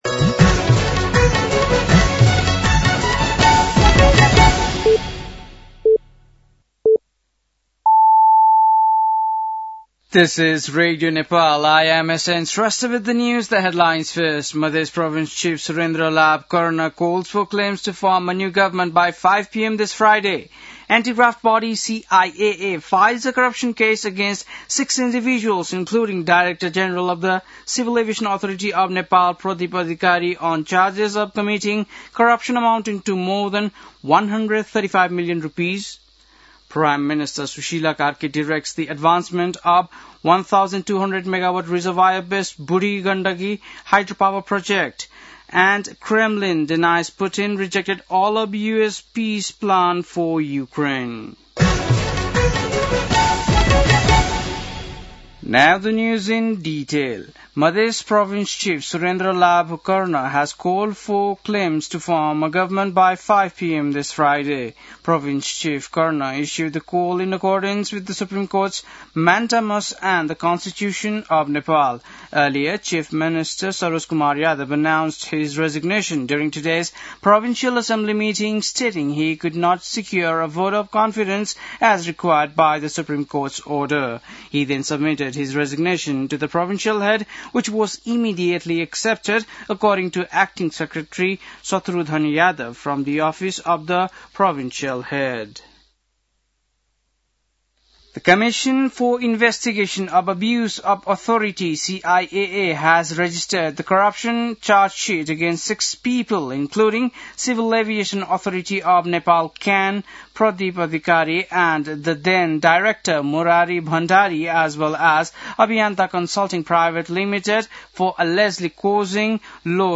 बेलुकी ८ बजेको अङ्ग्रेजी समाचार : १७ मंसिर , २०८२